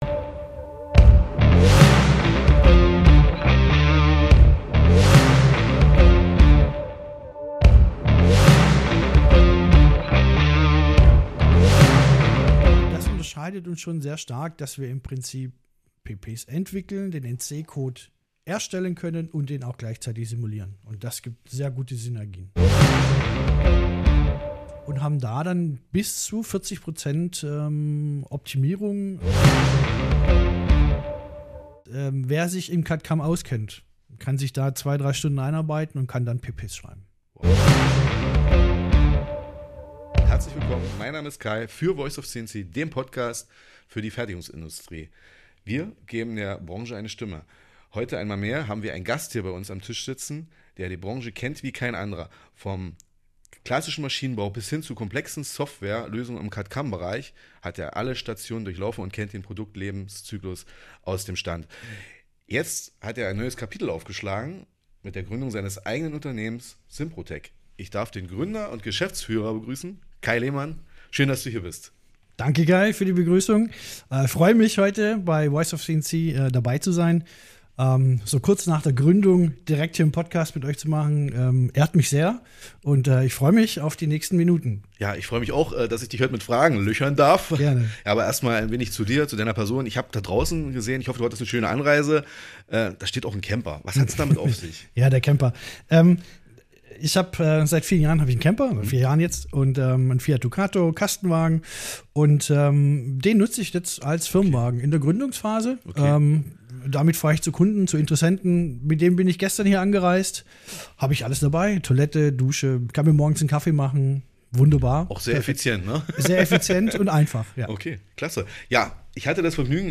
Mit SIMPROTEC schlägt er nun ein neues Kapitel auf: NC-Simulation, Postprozessor-Entwicklung und Laufzeitoptimierung aus einer Hand. Das erwartet euch im Interview: